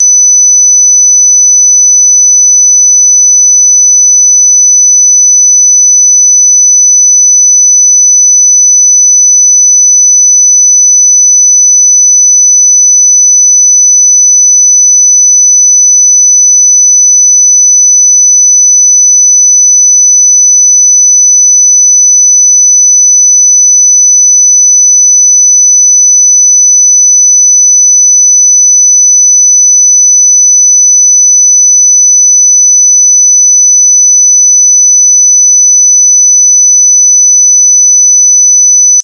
周波数6000Hzは、モスキート音として知られる高周波音であり、聞く者にとって特に注目すべき特性を持つ音です。